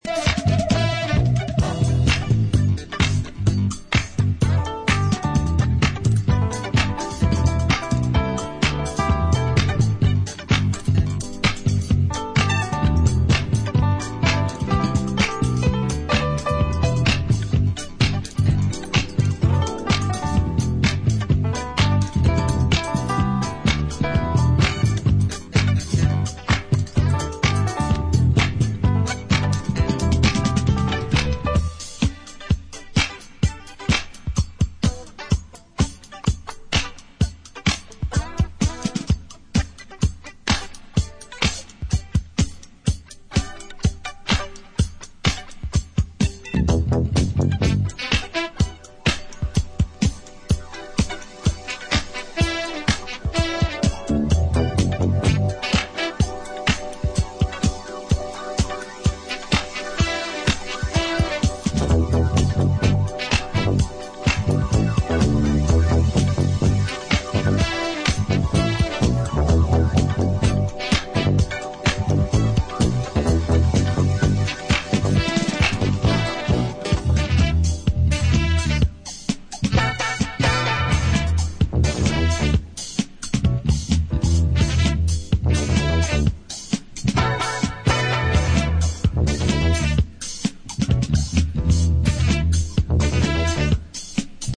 disco-funk
Disco House